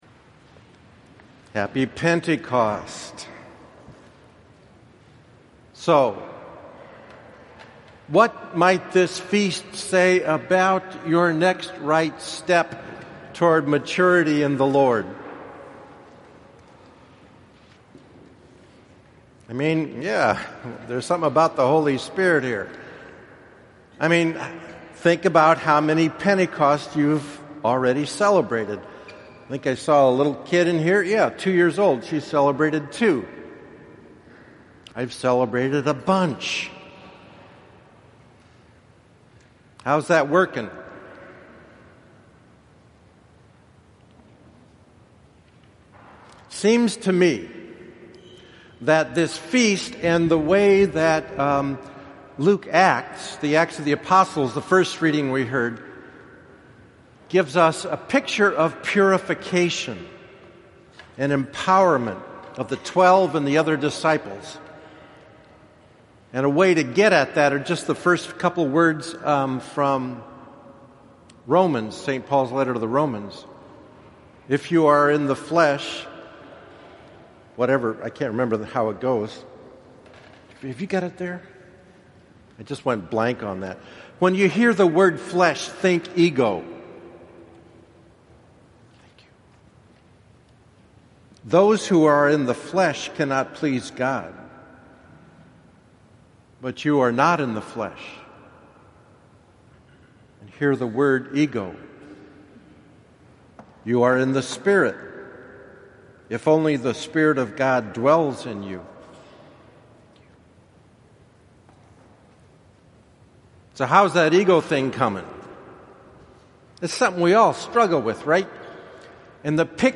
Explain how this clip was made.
From earlier in the day (11:30 am Mass), this homily has more word study and background from Luke’s gospel leading into the descent of the Spirit.